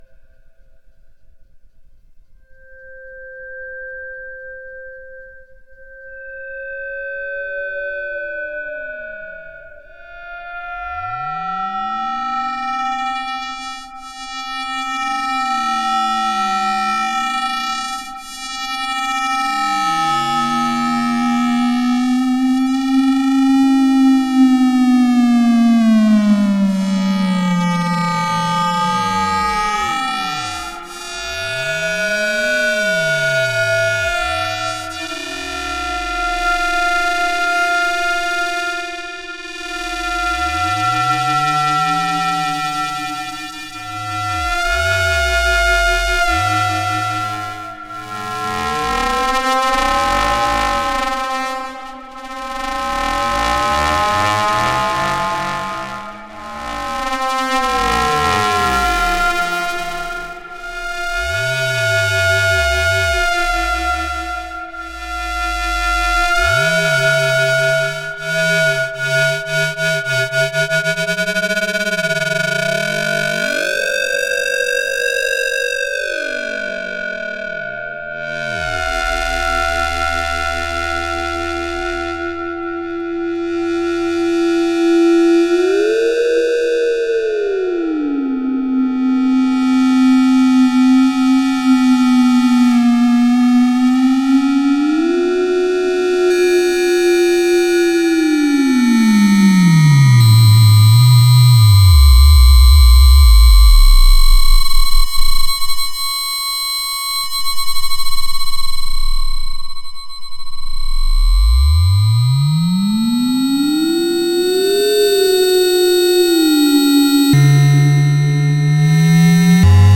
AM/FM
Been messing with ring modulation (4 quadrant AM) a bit, it's pretty simple to just multiply DSP signals when you have a numeric multiplier in your CPU. It's more interesting I think when one of the signals is fixed and the other is variable.